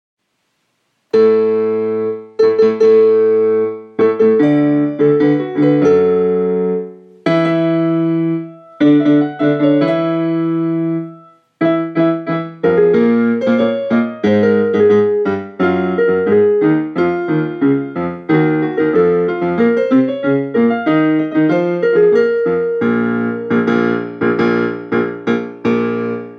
〜前奏〜(キューバ国歌)